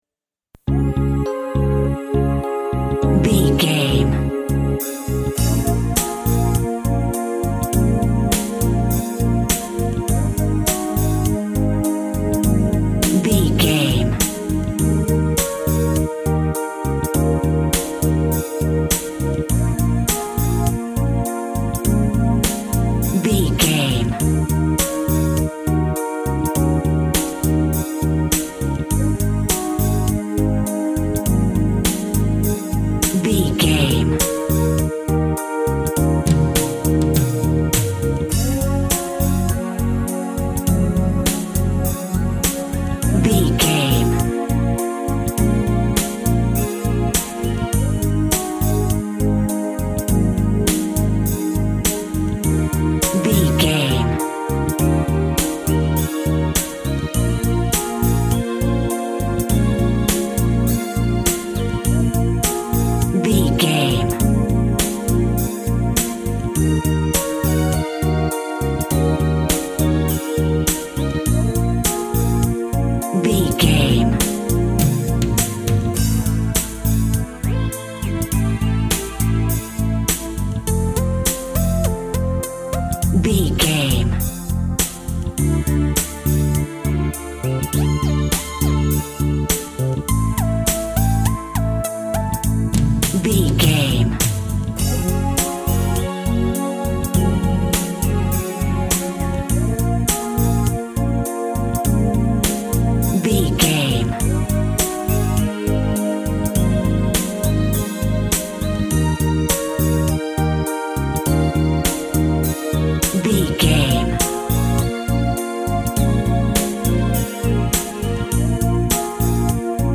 Eighties Talk Show Music.
Ionian/Major
D♭
Slow
hypnotic
dreamy
tranquil
smooth
drums
bass guitar
synthesiser
retro
pop
electronic
synth bass
synth lead